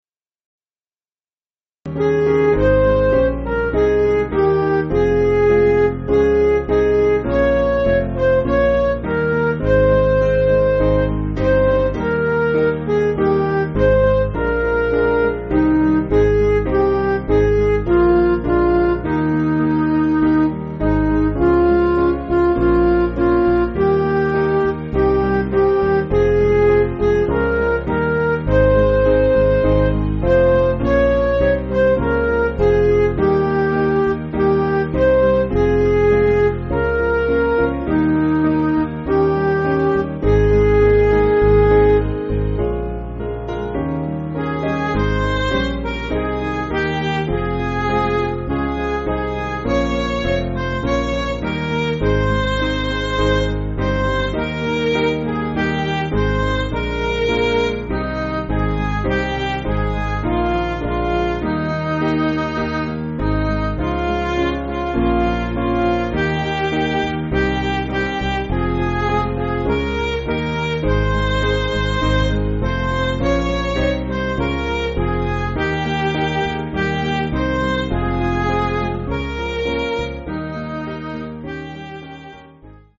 Piano & Instrumental
(CM)   5/Ab